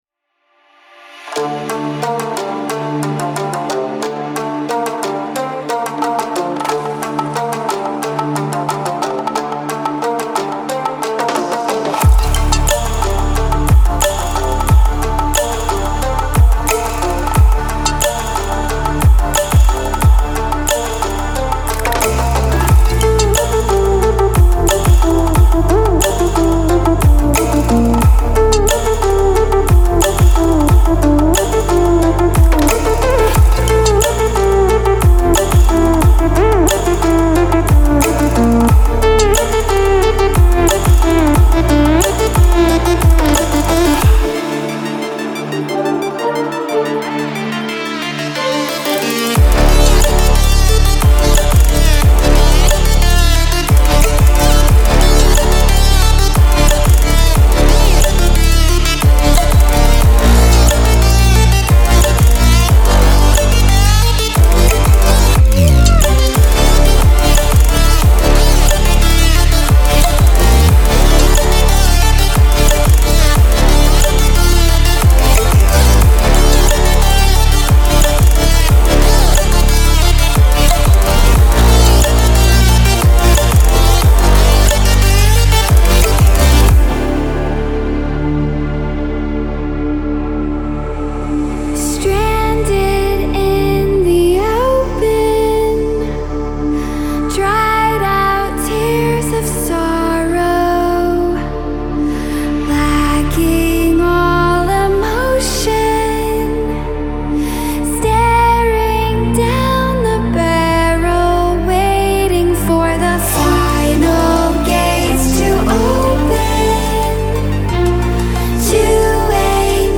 سبک ریمیکس